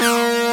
WEEE.WAV